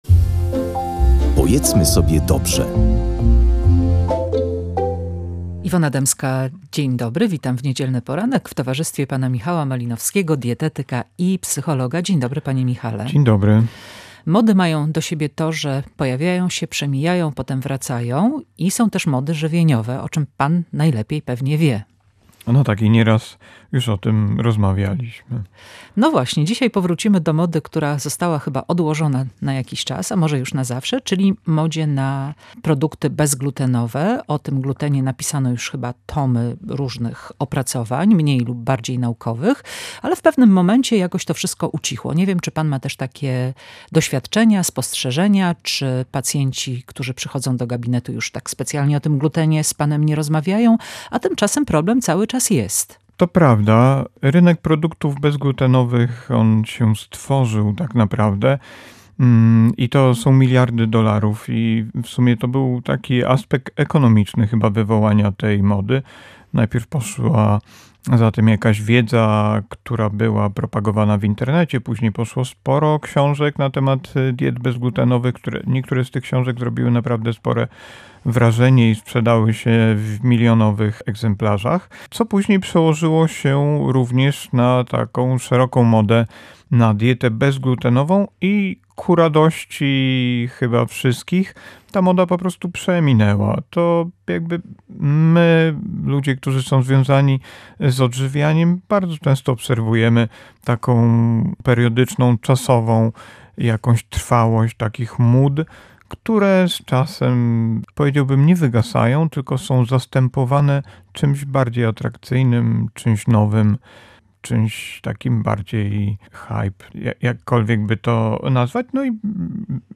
Nie taki gluten straszny, ale celiakia to już poważna sprawa. Rozmowa z dietetykiem